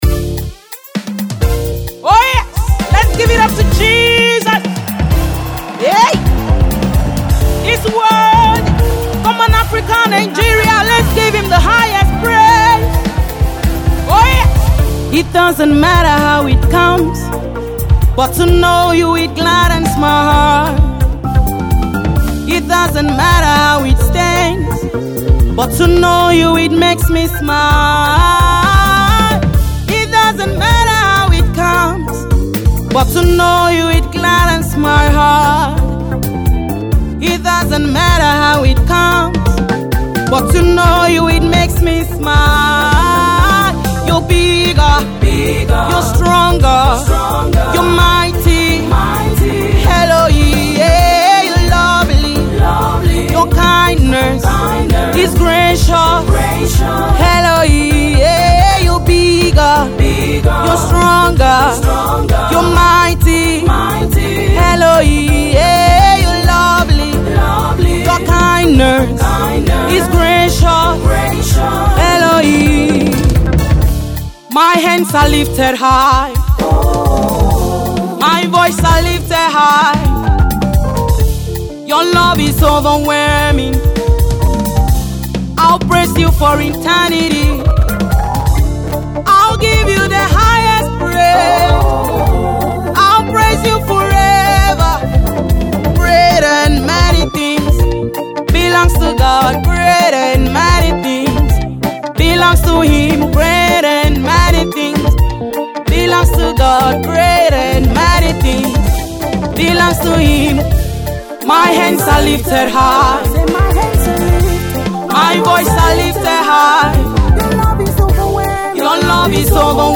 Prolific Gospel minister and song writer